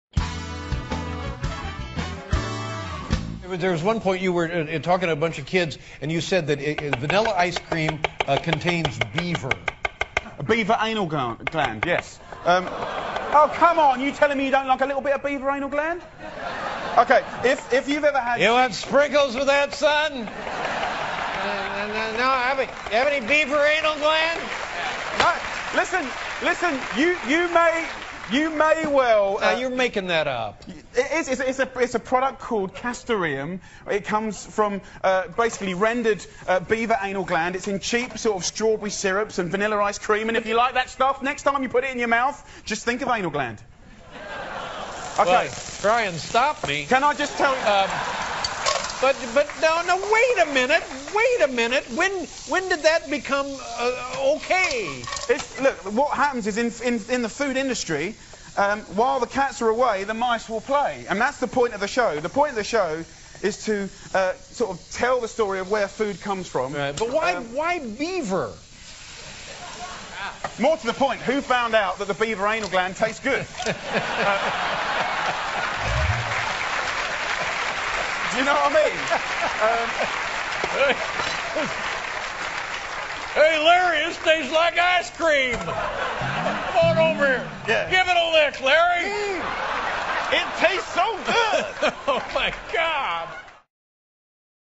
访谈录 2011-04-13&04-15 食神吉米·奥利弗专访 听力文件下载—在线英语听力室